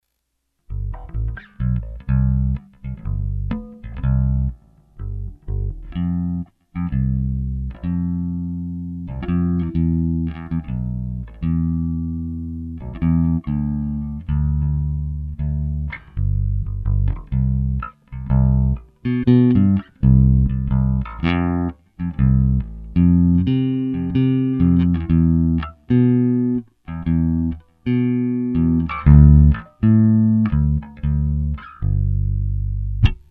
3-4 沒有壓縮動作的Bass 訊號波形。
unComp.mp3